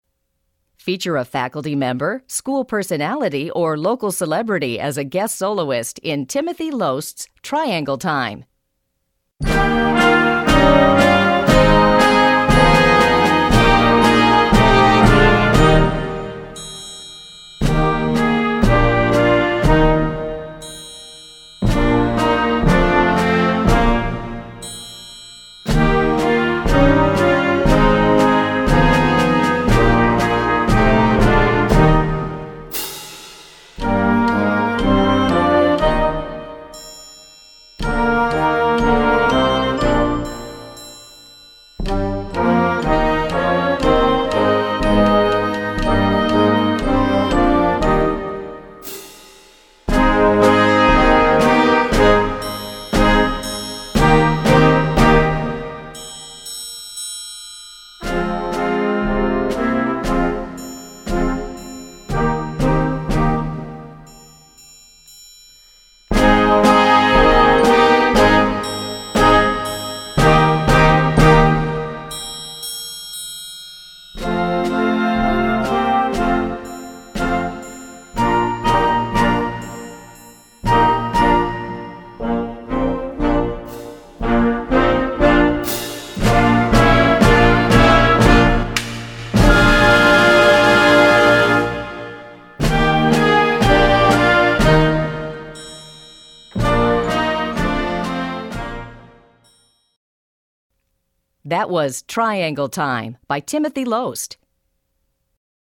Voicing: Triangle w/ Band